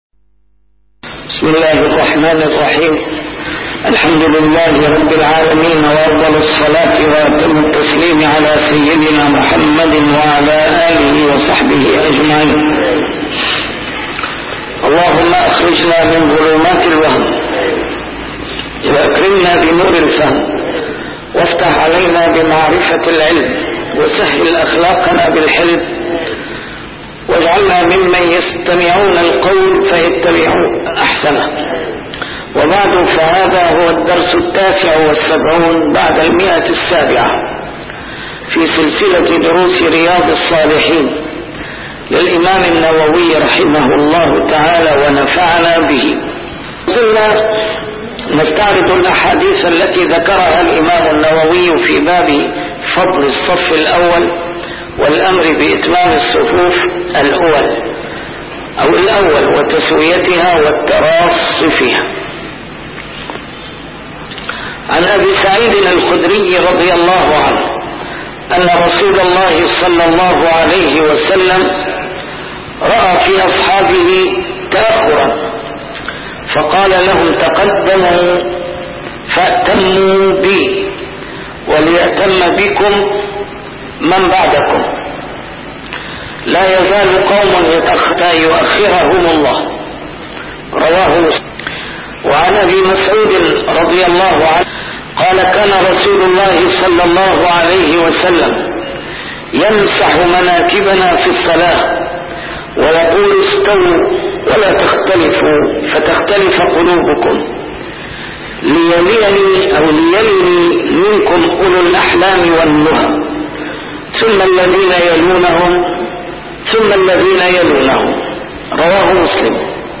A MARTYR SCHOLAR: IMAM MUHAMMAD SAEED RAMADAN AL-BOUTI - الدروس العلمية - شرح كتاب رياض الصالحين - 779- شرح رياض الصالحين: فضل الصف الأول